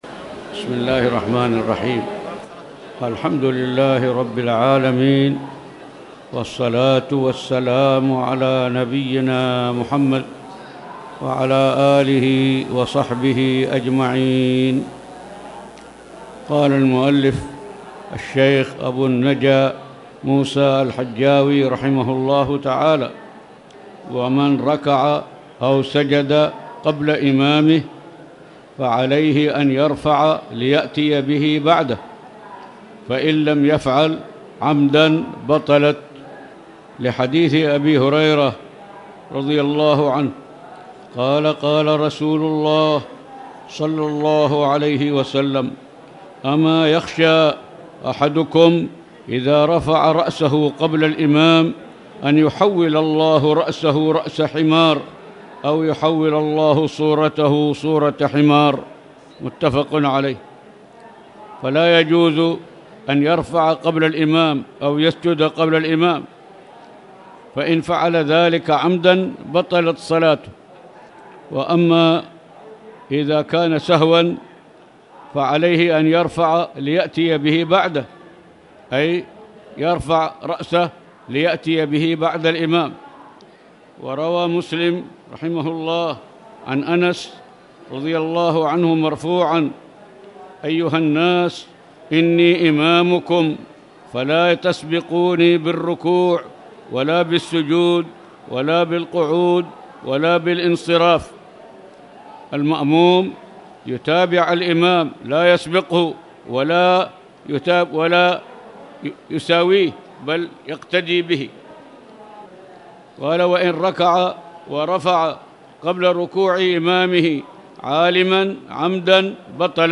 تاريخ النشر ١٤ رجب ١٤٣٨ هـ المكان: المسجد الحرام الشيخ